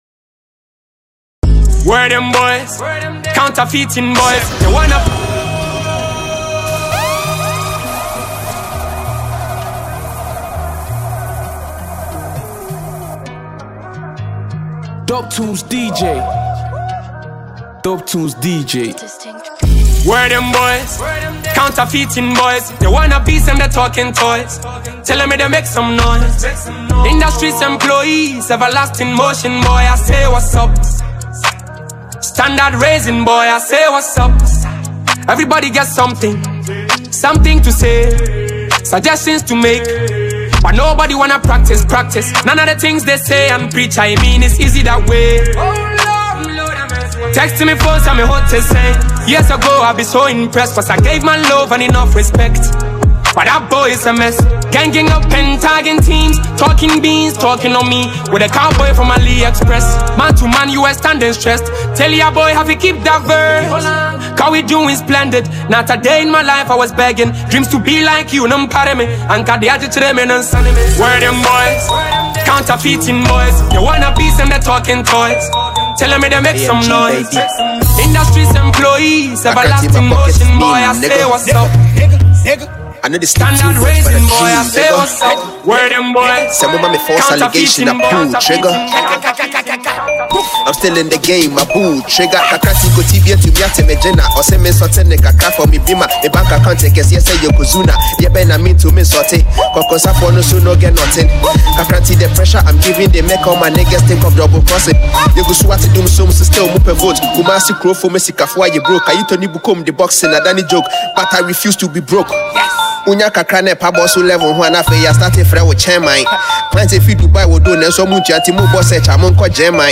a heavy mix of Ghana’s best rap bangers.